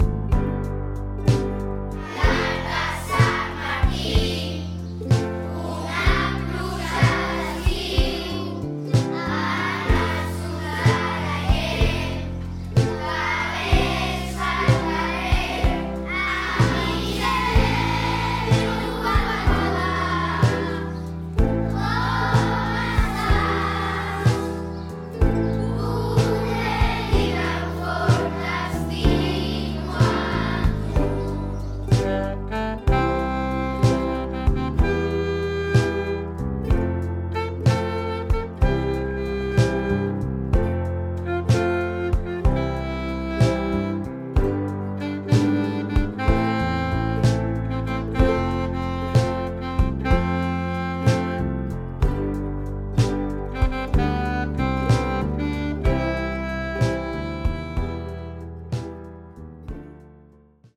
No us perdeu el solo de saxo! És espectacular!